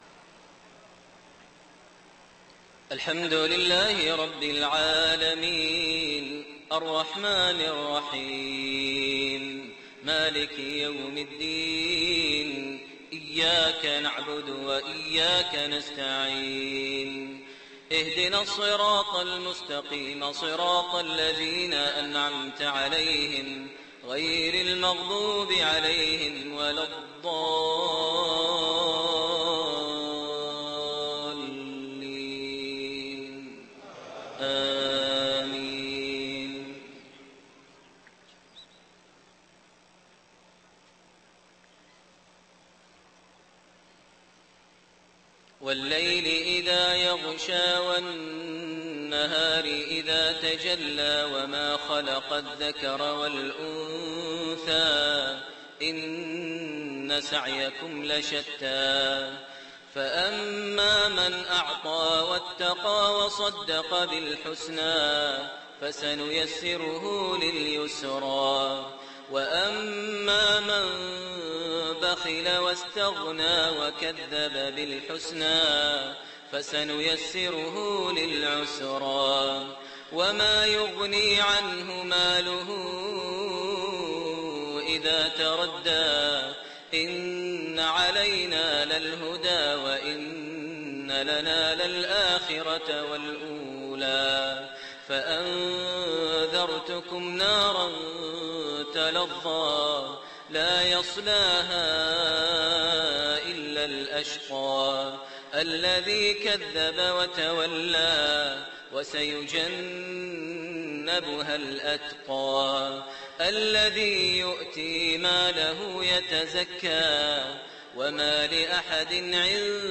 Maghrib prayer Surat Al-Lail // Al-Masad > 1429 H > Prayers - Maher Almuaiqly Recitations